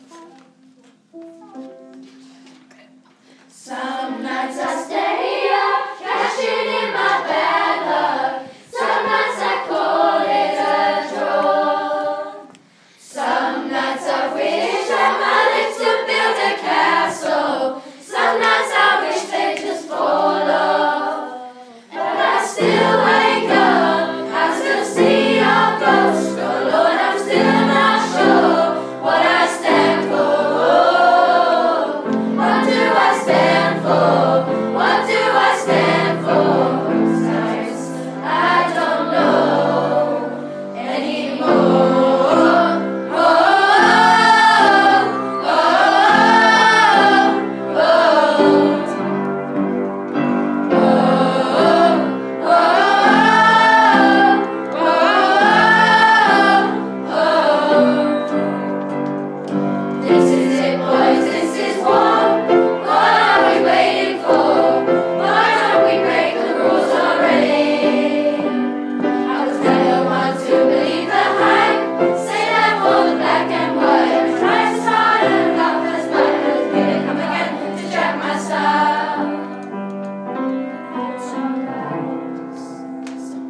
Performed by Melodic Minors in their rehearsal.